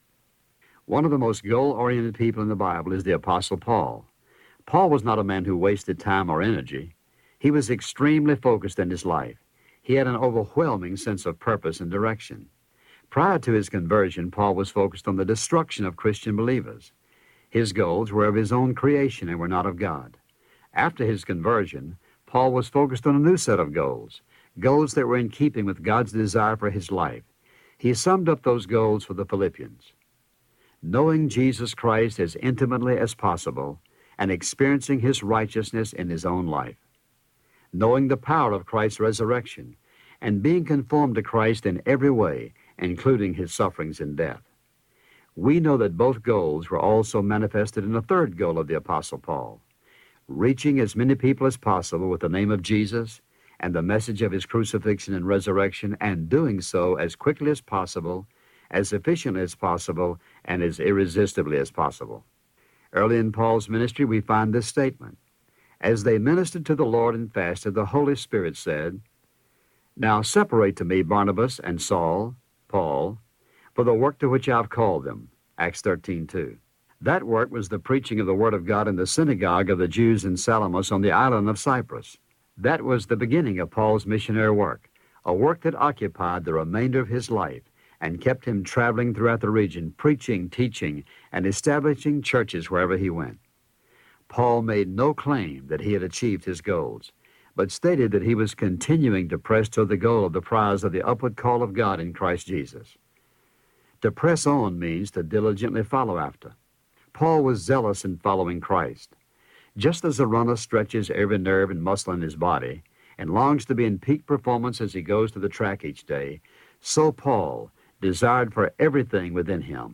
Narrator